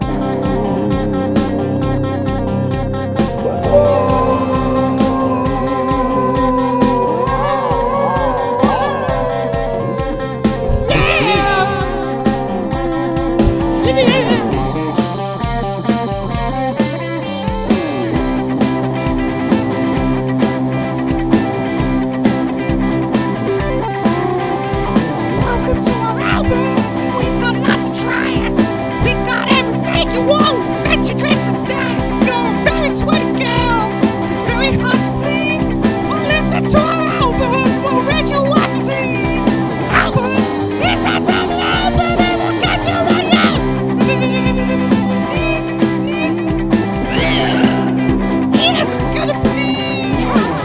brief blast of rock 'n' roll carnage